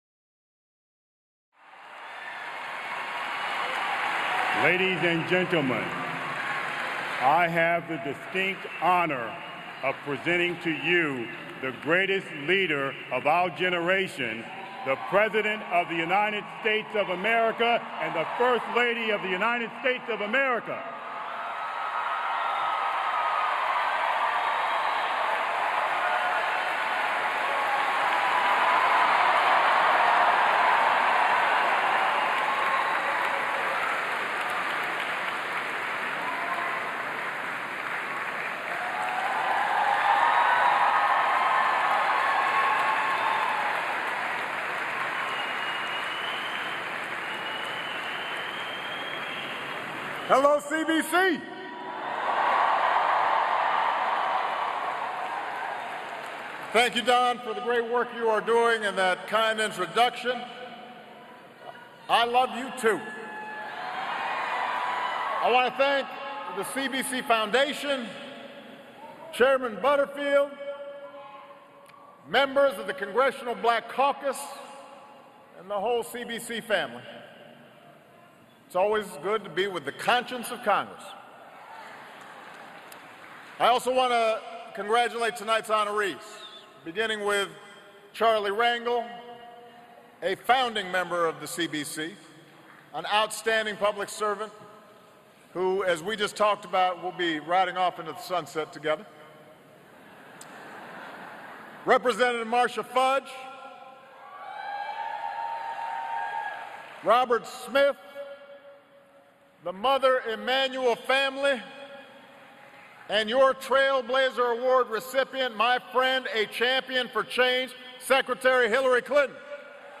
U.S. President Barack Obama speaks at the the Congressional Black Caucus Foundation's 46th Annual Legislative Conference Dinner